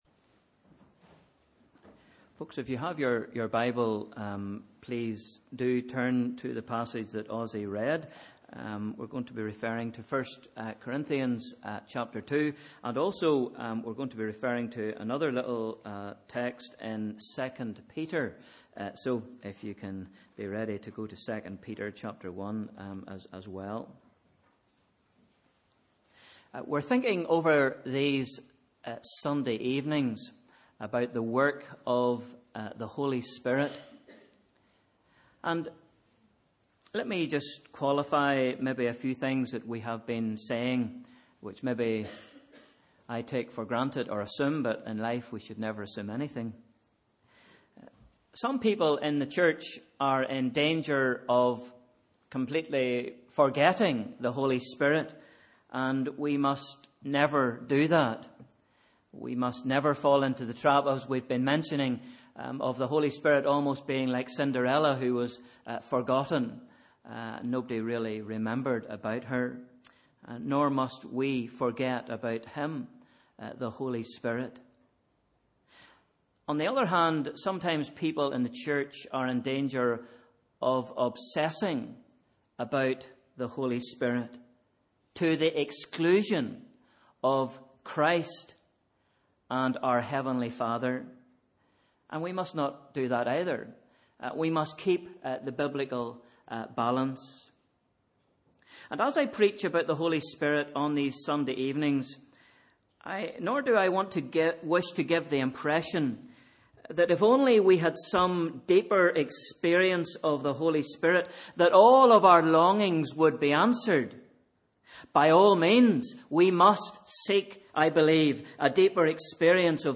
Sunday 31st January 2016 – Evening Service